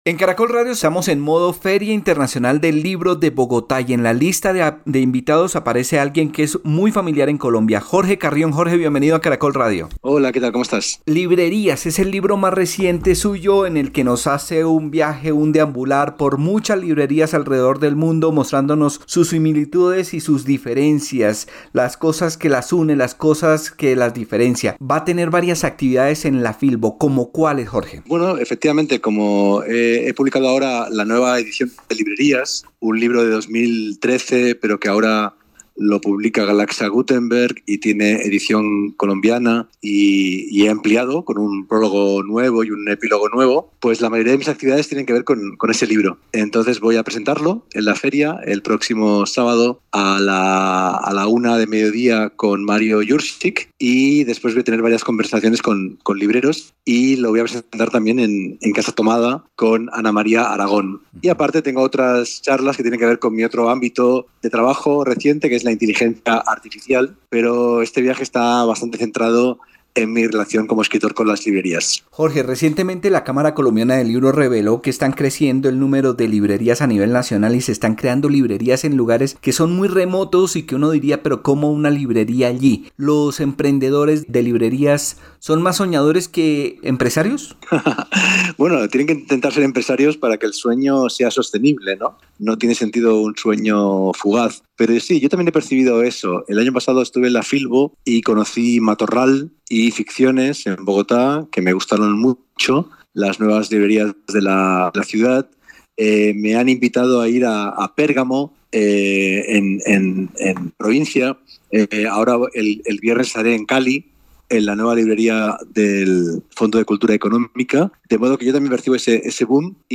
Filbo 2025
En los micrófonos de Caracol Radio, Carrión dio a conocer como la Inteligencia Artificial (IA), puede ser una herramienta útil para la construcción de un espacio para los libros, “las librerías tienen que ser refugios de papel, debe haber un contacto visual y la IA puede brindar esas herramientas (videos, recomendados, textos), que nos permitan tener datos para que el público se sienta acogido, sin dejar a un lado el papel”, aseguró el escritor.